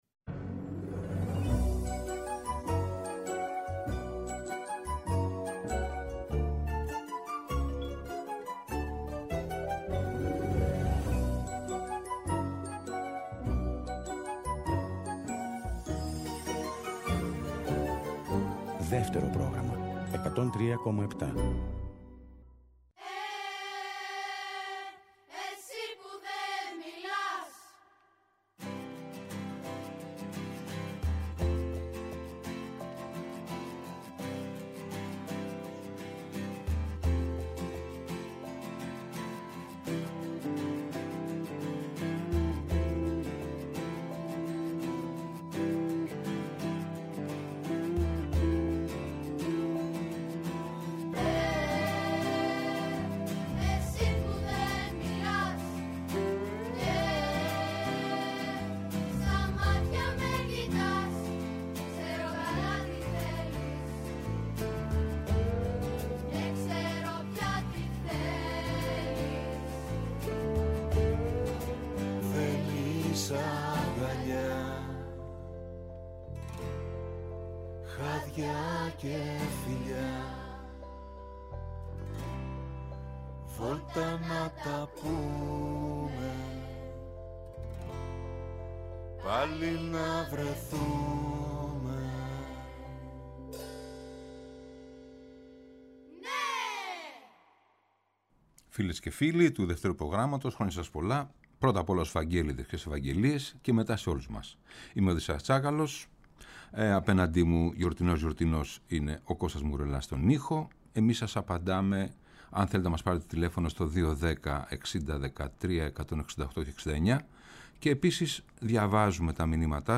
με τραγούδια που αναφέρονται στην πατρίδα, στην Ελλάδα του παρελθόντος, του παρόντος και στις ευχές για το μέλλον της.